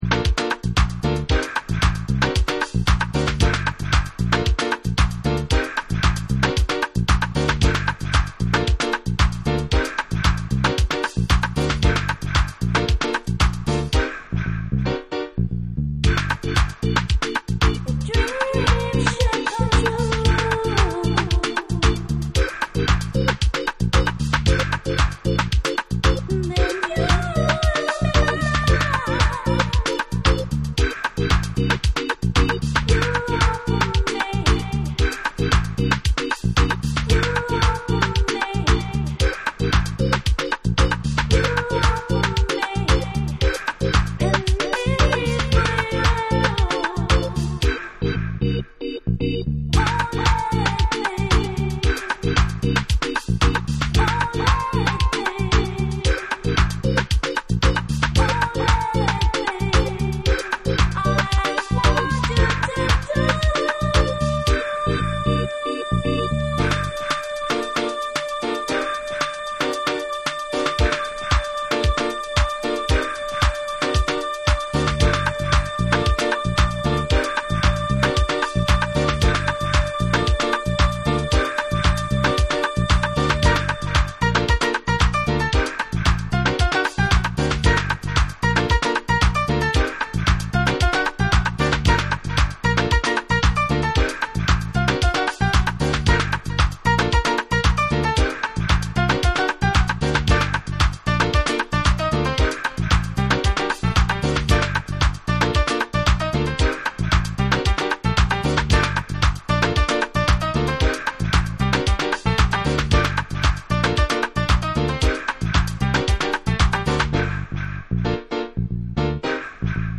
ラテン風味のライトなトラックに涼しげなヴォーカルが絡んだミッド・テンポの絶品ジャジーハウス
TECHNO & HOUSE